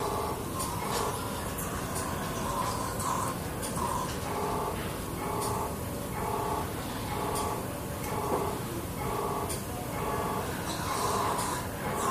Intensive Care Unit Machinery, Pressure, Pump, IV Dispenser